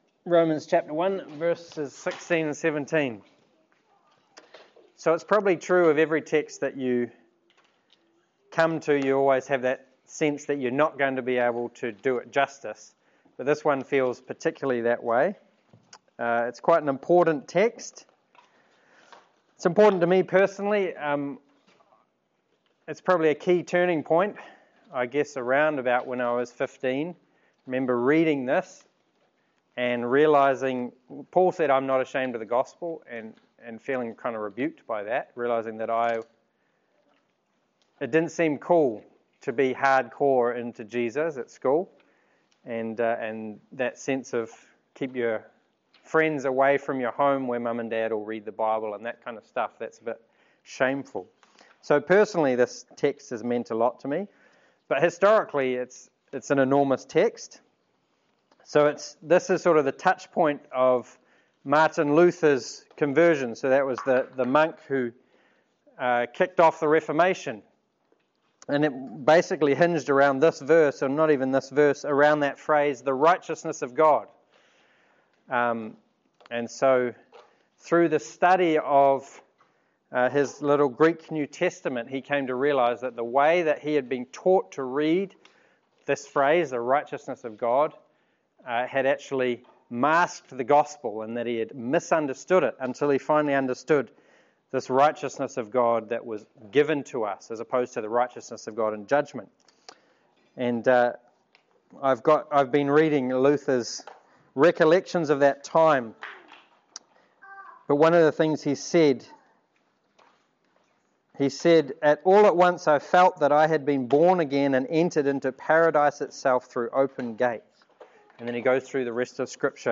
Romans 1:16-17 Service Type: Sermon There are aspects of the gospel that are looked down upon today